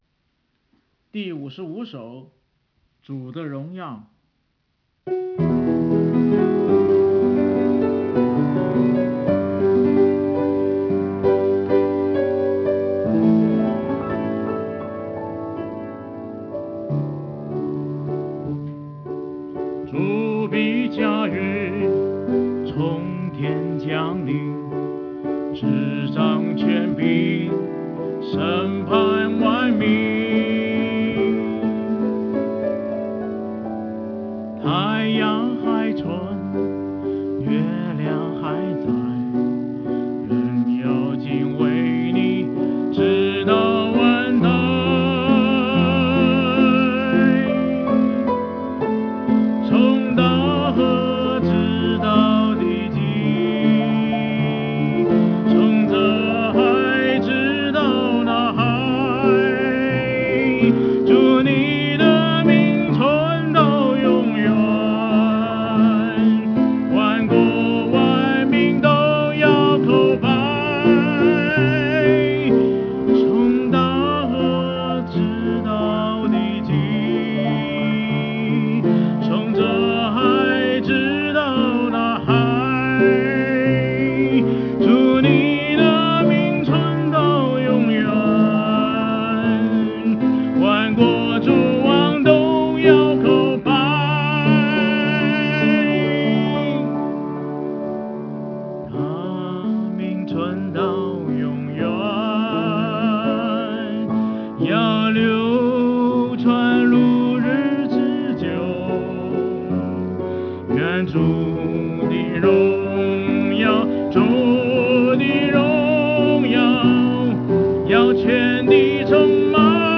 诗歌敬拜-2024年02月04日